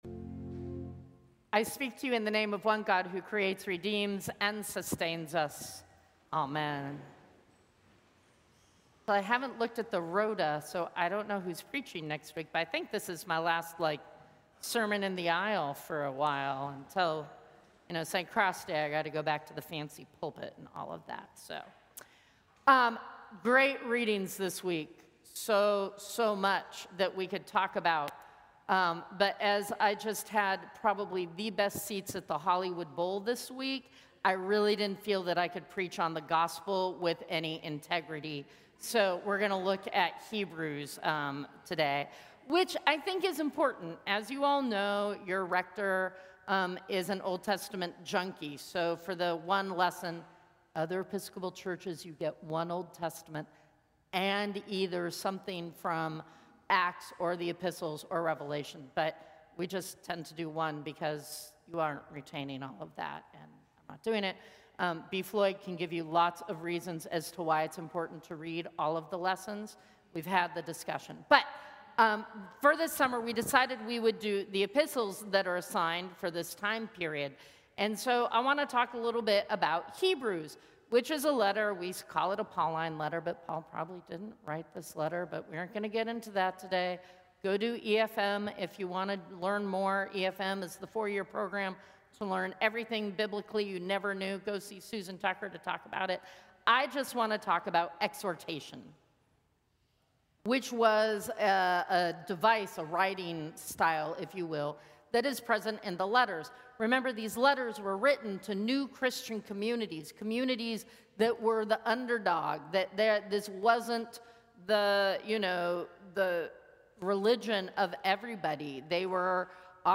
Sermons from St. Cross Episcopal Church Twelfth Sunday after Pentecost Aug 31 2025 | 00:13:08 1x 00:00 / 00:13:08 Subscribe Share Apple Podcasts Spotify Overcast RSS Feed Share Link Embed